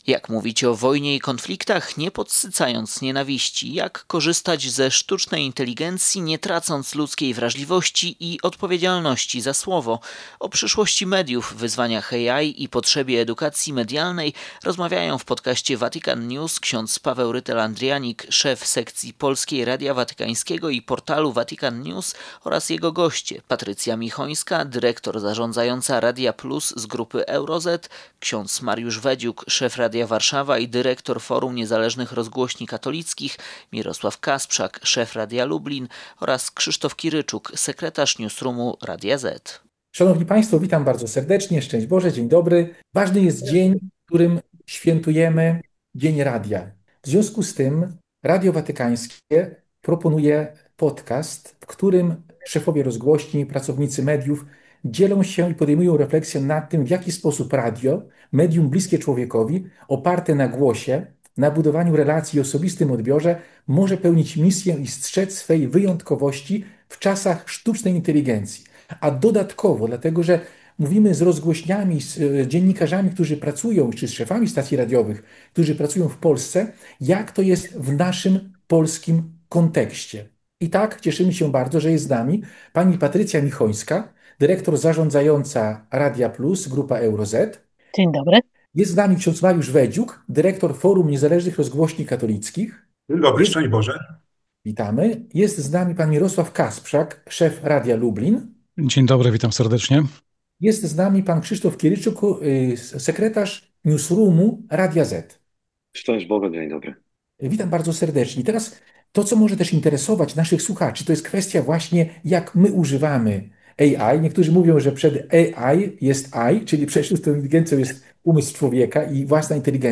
W podcaście Vatican News, przygotowanym z okazji Dnia Radia, przedstawiciele polskich rozgłośni, w tym Radia Lublin, zastanawiali się nad przyszłością mediów w świetle słów Papieży Franciszka i Leona XIV o potrzebie „rozbrojenia komunikacji”. Specjaliści rozmawiali o tym, jak nie utracić samodzielnego myślenia w epoce sztucznej inteligencji i nie podsycać konfliktów słowem. Zdaniem dziennikarzy, AI może wspierać media, ale nie może zastąpić człowieka, relacji i odpowiedzialności za słowo.